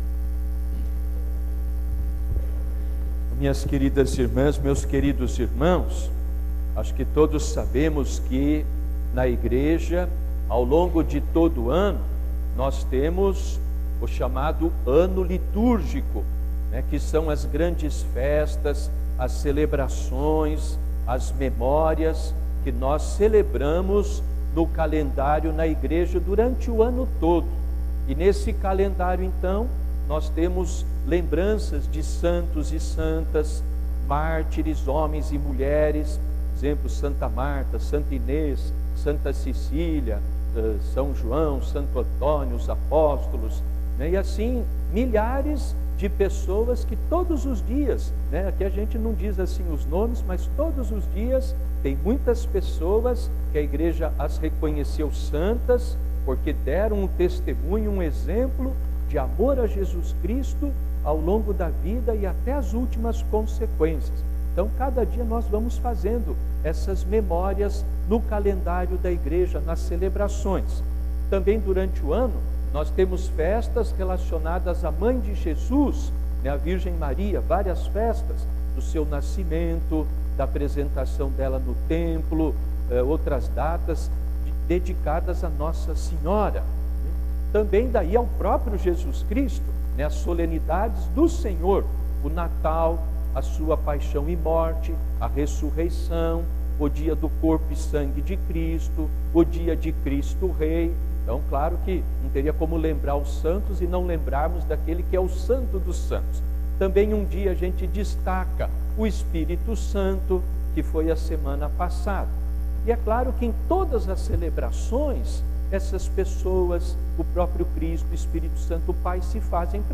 Homilia
para a festa da Santíssima Trindade